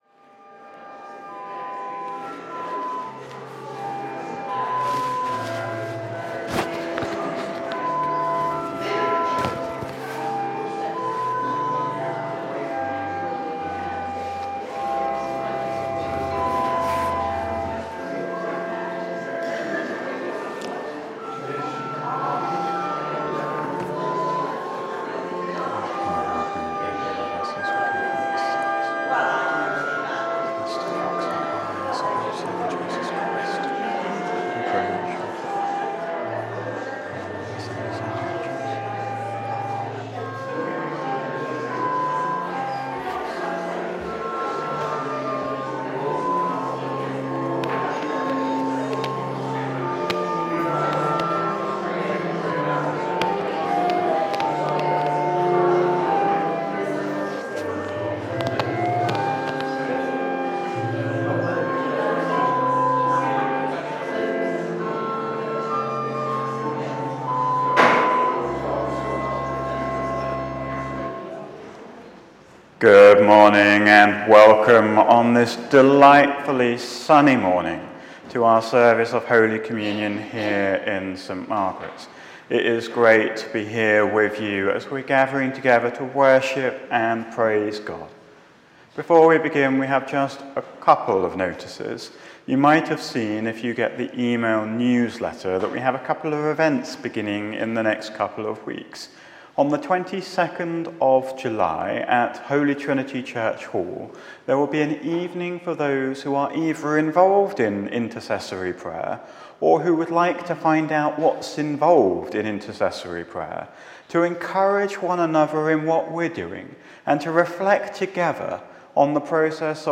Service-14-7-24.mp3